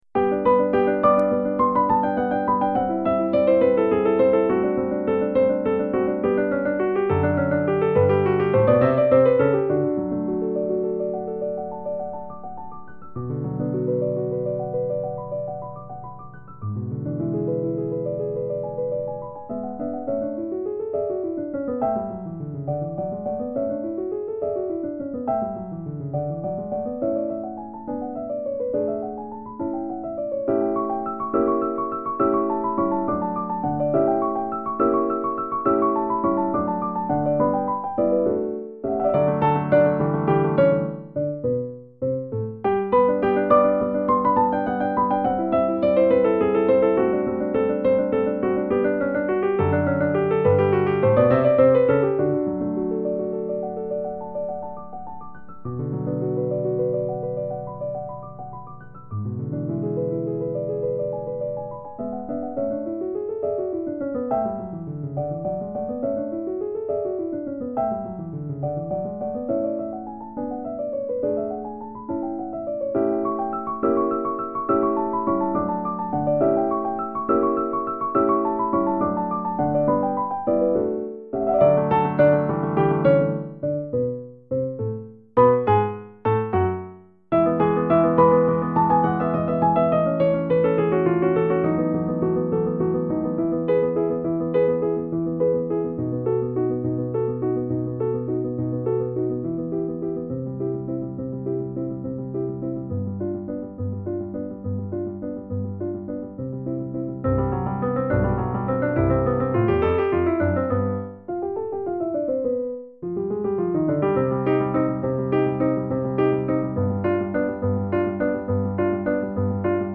The 25th piano sonata written by Beethoven is rather short (in spite of having three movements), quick and playful.
The midi file was downloaded from The Classical Music Archives.